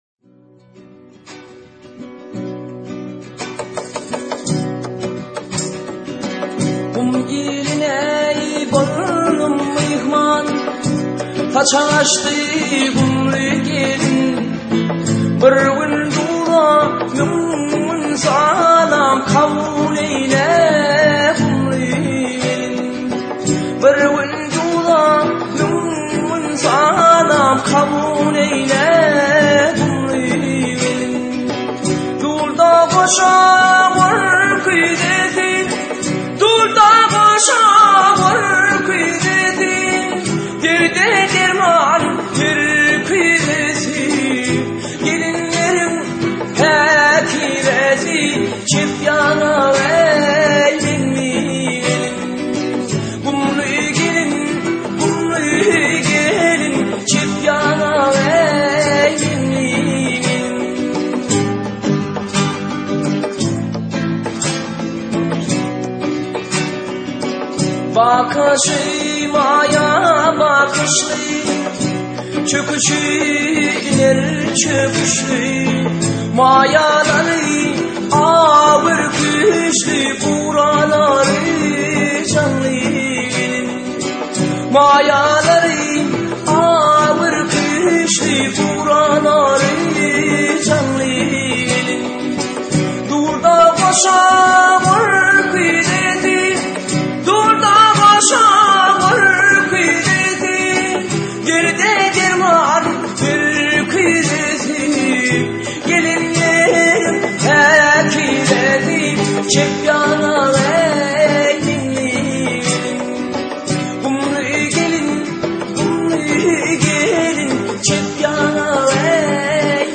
یه آهنگ عاشقانه ترکمنی